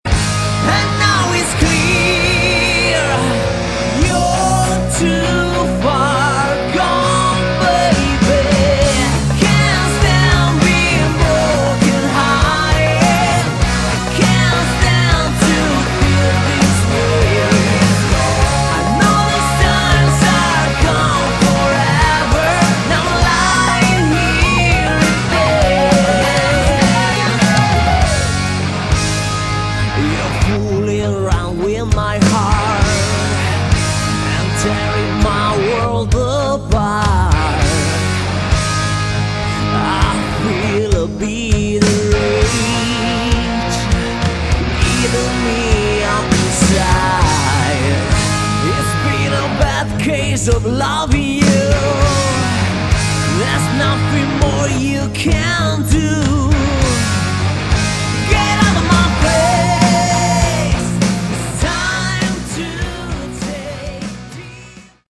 Category: Melodic Rock
vocals
guitars
keyboards
bass
drums